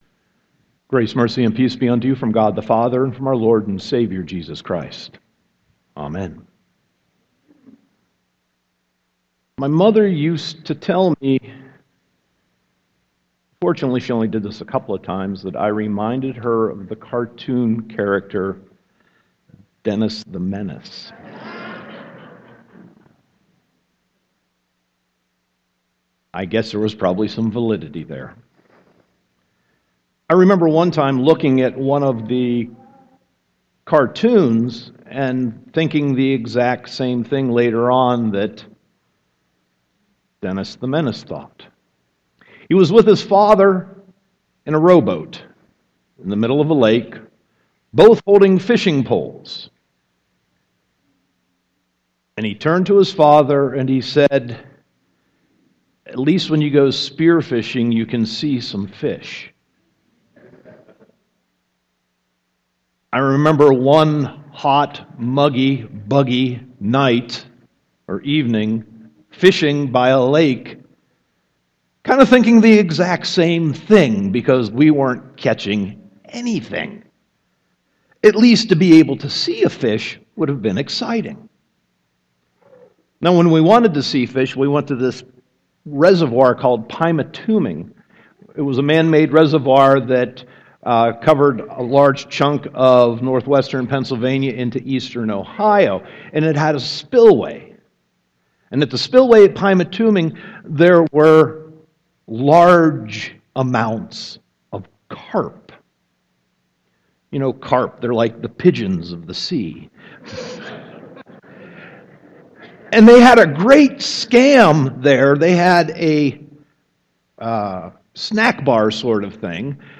Sermon 4.10.2016